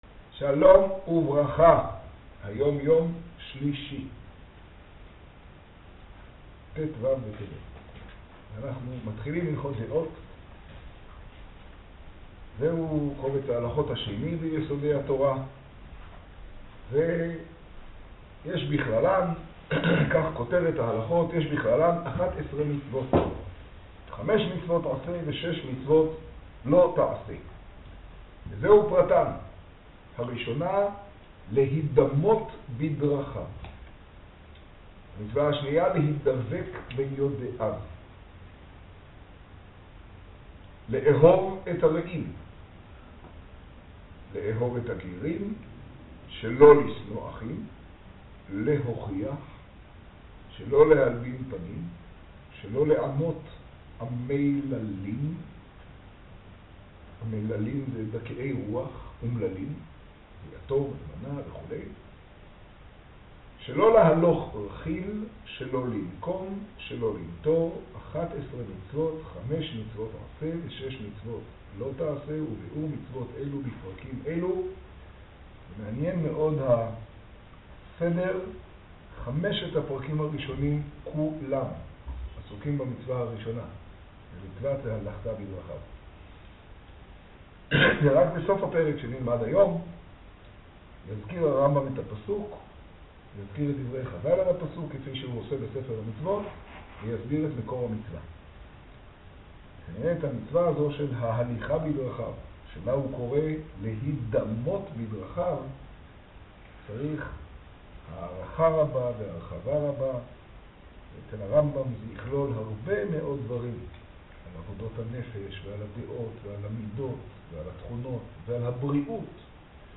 השיעור במגדל, טו טבת תשעה.